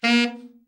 TENOR SN  16.wav